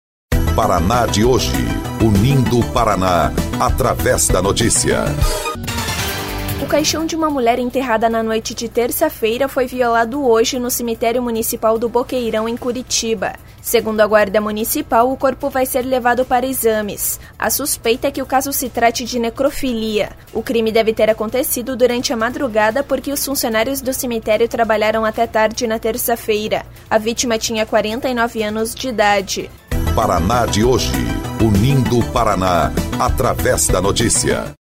BOLETIM – Suspeita de necrofilia é registrada em cemitério de Curitiba